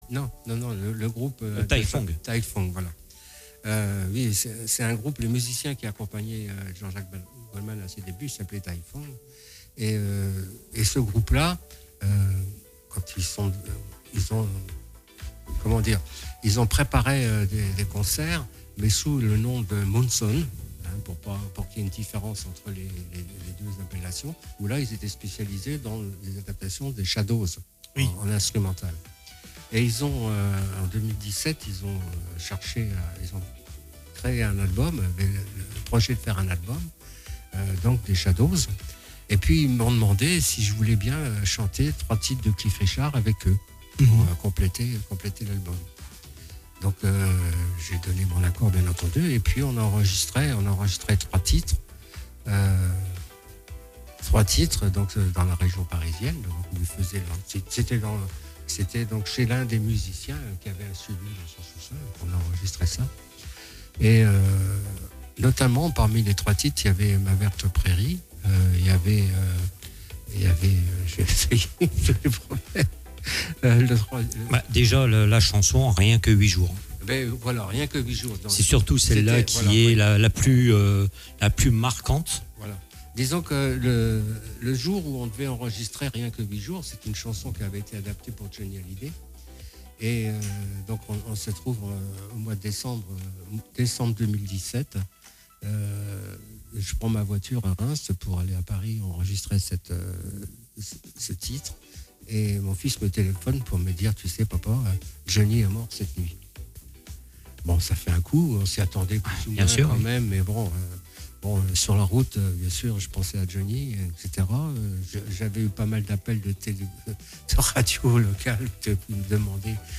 Tous les dimanches de 14h30 à 17h00En direct sur ANTENNE 87À (re)découvrir en podcast sur notre site web